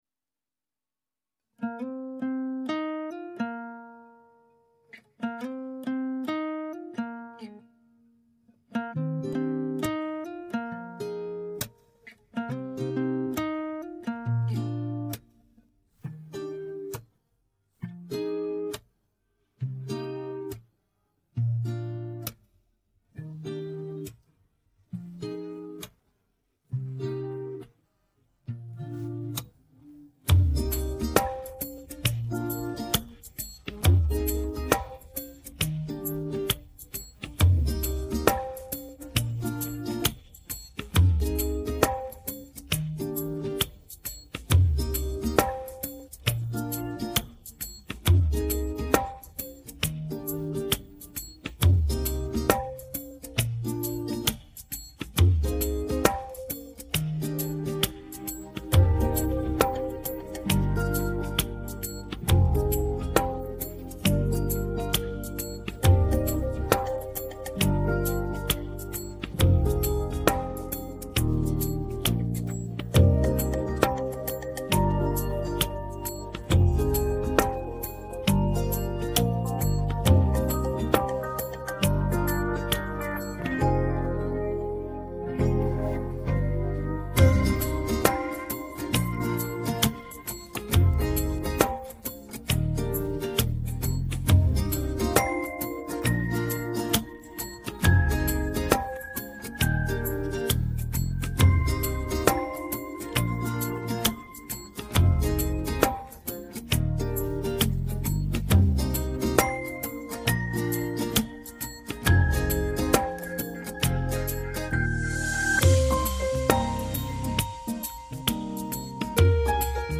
Baggrundsmusik